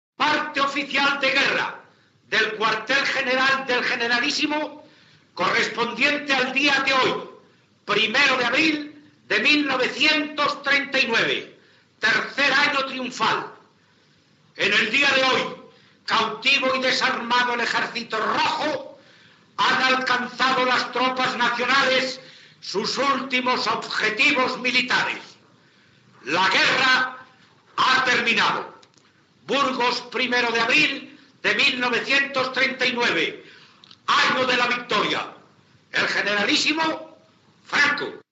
Recreació de l'últim comunicat de guerra, redactat pel general Franco de l'exèrcit nacional, a la Guerra Civil espanyola.
Informatiu
El locutor Fernando Fernández de Córdoba va recrear el que va llegir l'any 1939, anys després.